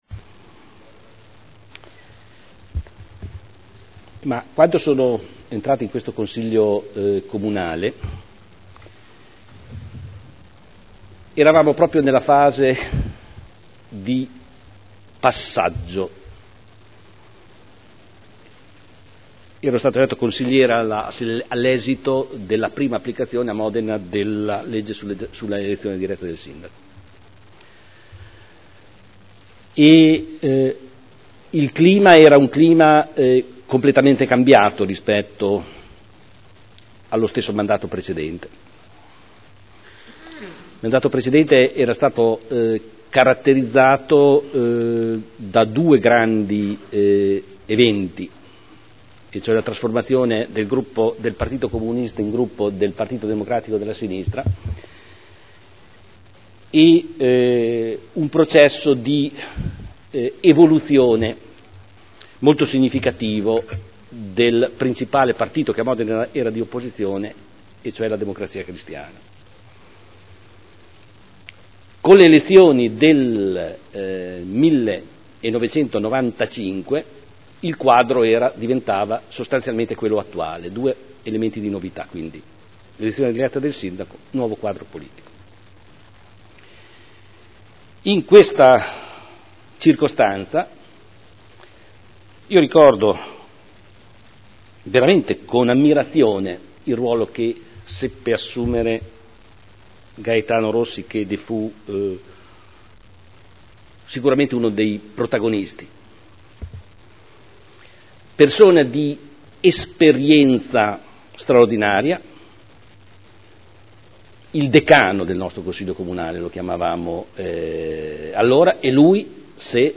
Seduta del 26 marzo. Ricordo del Sindaco dello scomparso Gaetano Rossi (ex consigliere comunale e segretario del partito liberale e padre del consigliere Nicola Rossi)